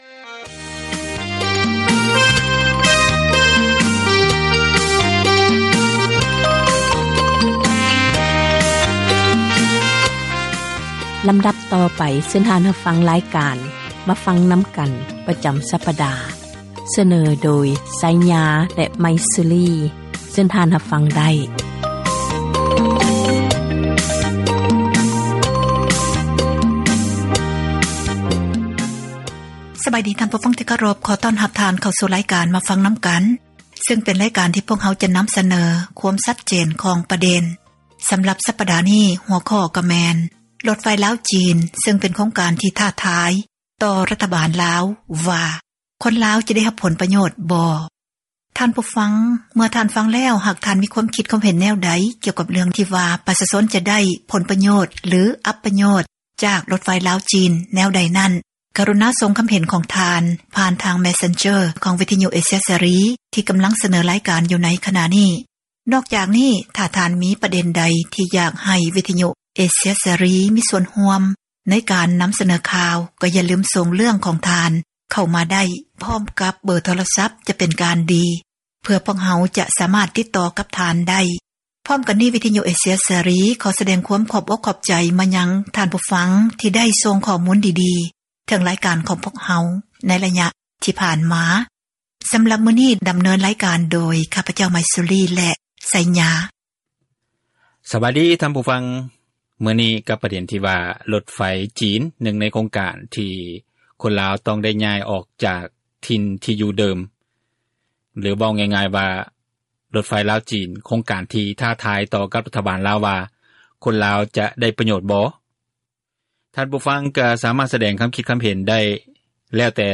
"ມາຟັງນຳກັນ" ແມ່ນຣາຍການສົນທະນາ ບັນຫາສັງຄົມ ທີ່ຕ້ອງການ ພາກສ່ວນກ່ຽວຂ້ອງ ເອົາໃຈໃສ່ແກ້ໄຂ